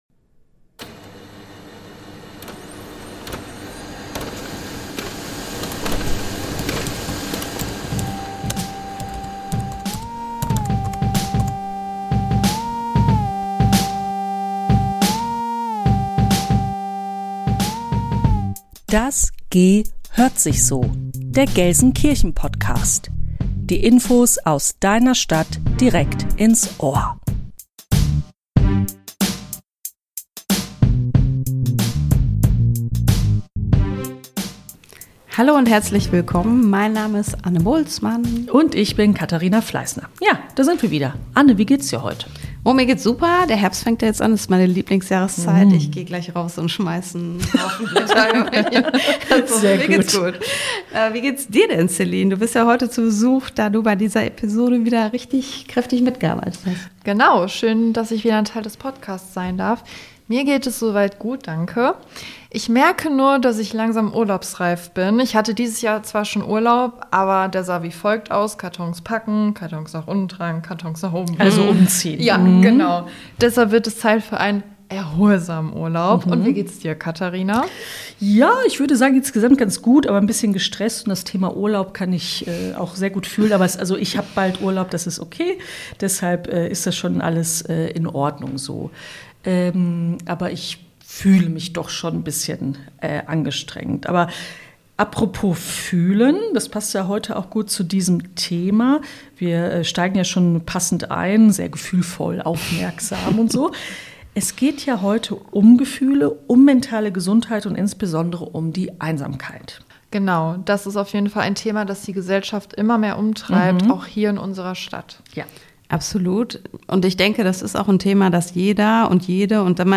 Wir sprechen mit Kolleginnen und Kollegen über die überraschenden Ergebnisse der neuen Einsamkeitsstudie der Stadt Gelsenkirchen und haben Angebote gegen Einsamkeit in dieser Stadt besucht. Ein buntes Sammelsurium an spannenden Möglichkeiten, Menschen kennenzulernen und etwas Spannendes zu erleben.